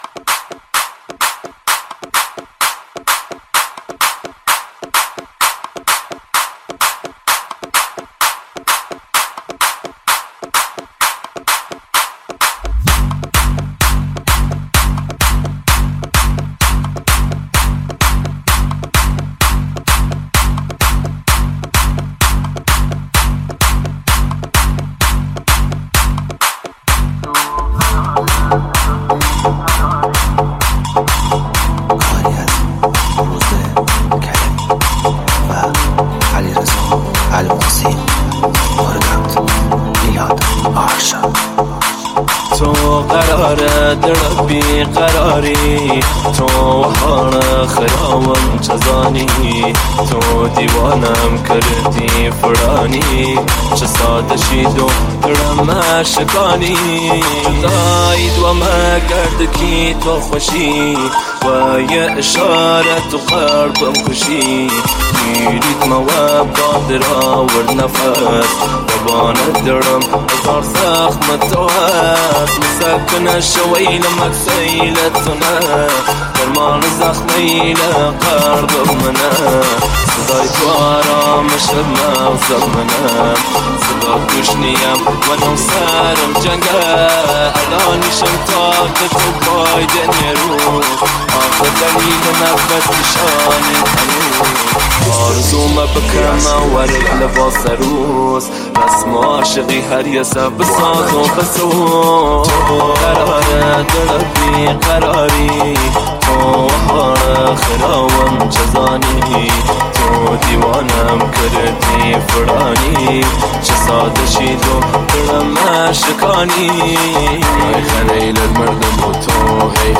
نسخه ریمیکس شده